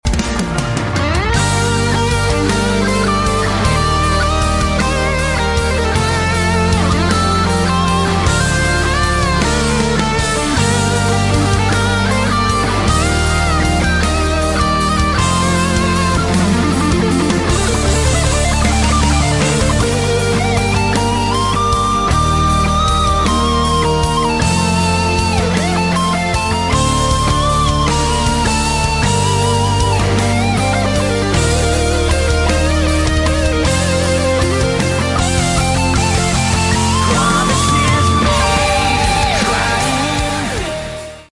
Guitar
FX: Boss GX-100 🔊 Amp: Boss Katana 50 Gen 3